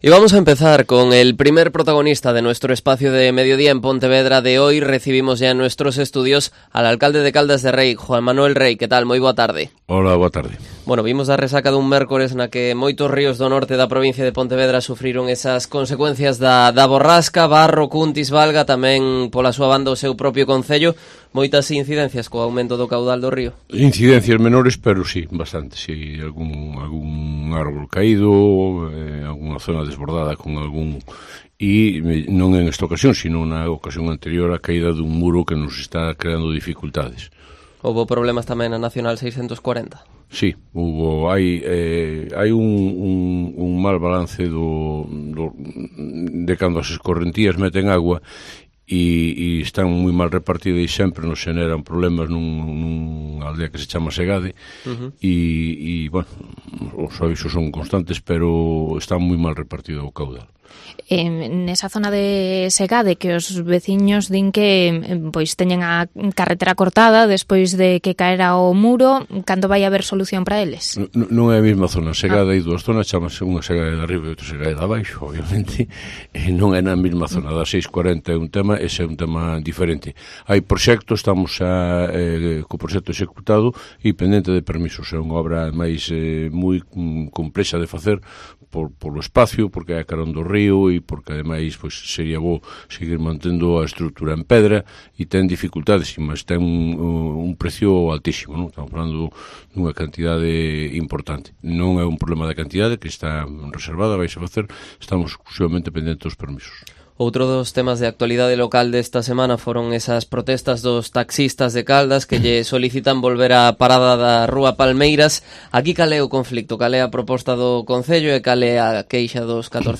Entrevista de Juan Manuel Rey, alcalde de Caldas de Reis